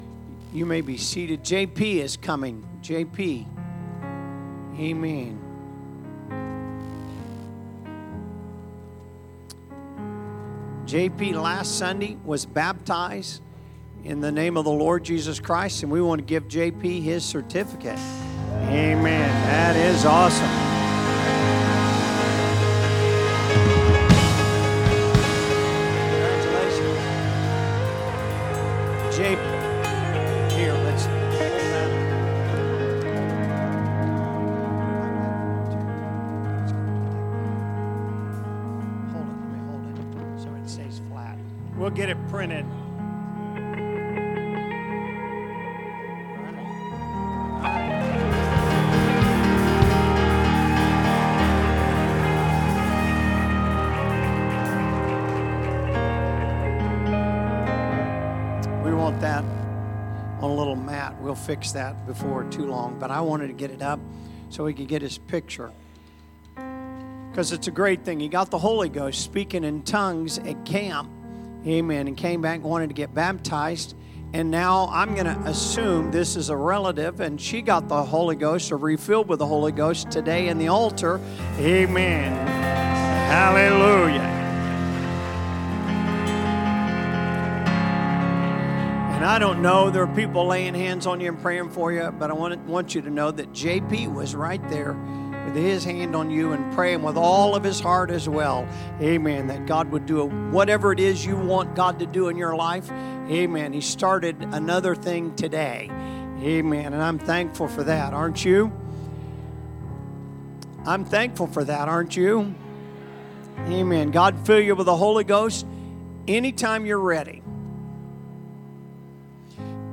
Sermons | Elkhart Life Church
Sunday Service - Part 2